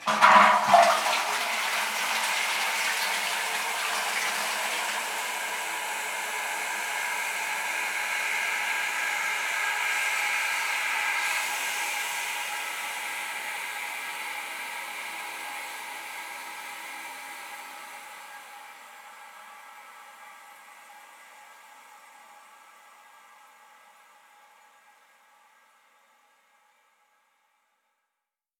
Cisterna de wáter 4
cisterna
Sonidos: Agua
Sonidos: Hogar